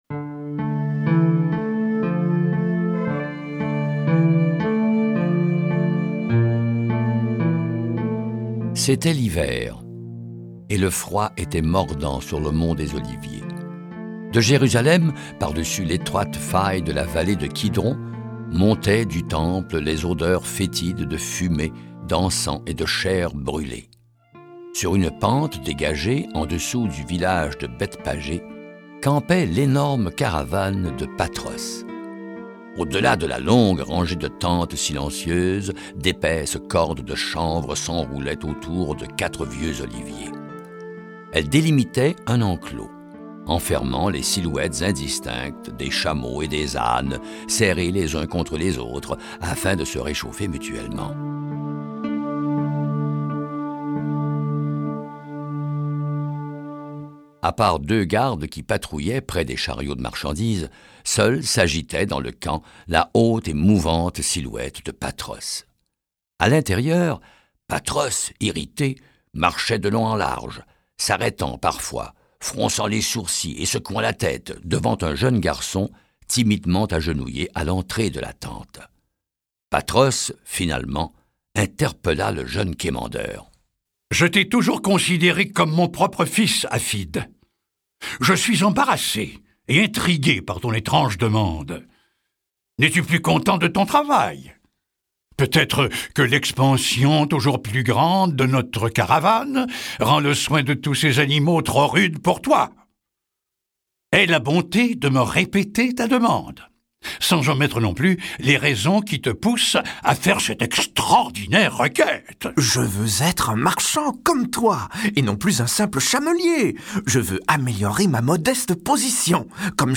Diffusion distribution ebook et livre audio - Catalogue livres numériques
Lu par Albert Millaire Durée : 57 minutes 14 , 40 € Ce livre est accessible aux handicaps Voir les informations d'accessibilité